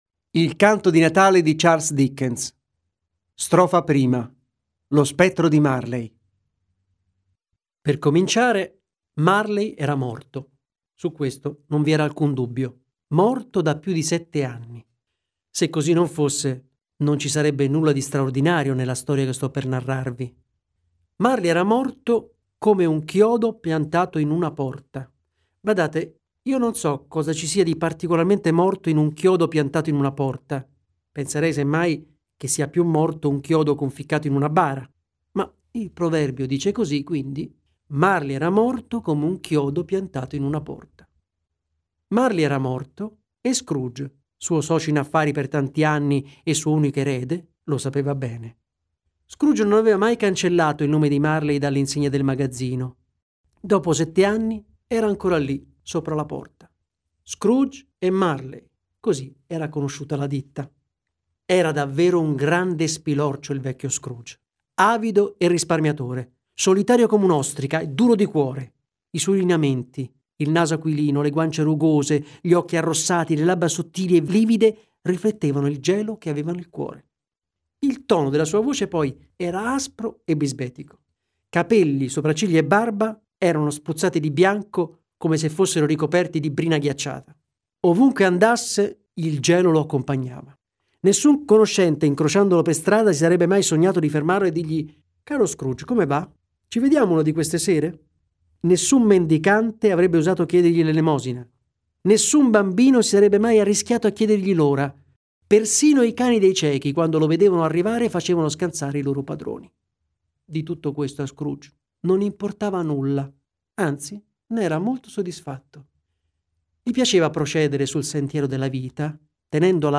Letto da: Giulio Scarpati
Audio libro